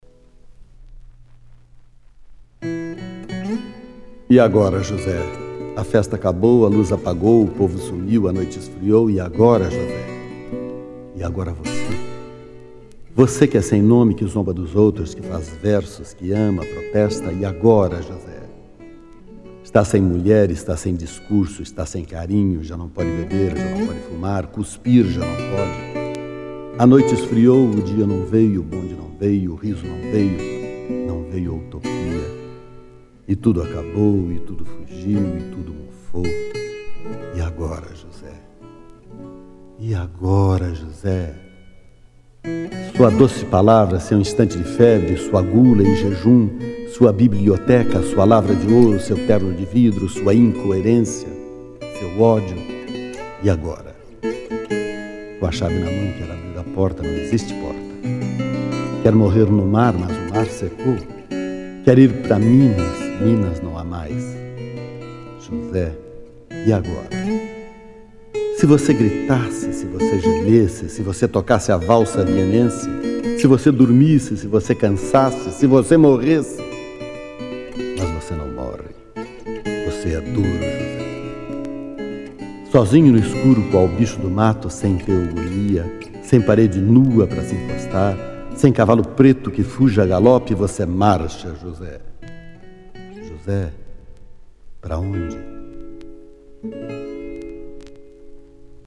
Carlos Drummond de Andrade interpretado por Lima Duarte - Músicas: Roberto Corrêa